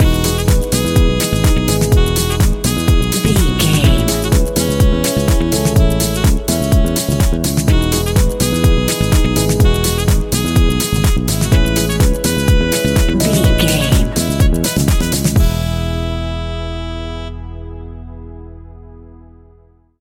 Ionian/Major
uplifting
energetic
bouncy
bass guitar
saxophone
electric piano
drum machine
synth
groovy